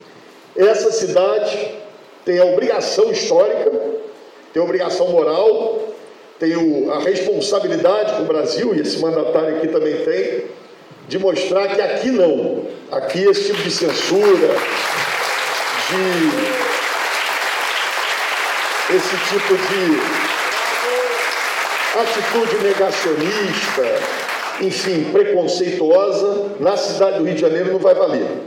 O prefeito Eduardo Paes participou do anúncio do Plano de retomada do Audiovisual Carioca, no Palácio da Cidade, na tarde desta terça-feira.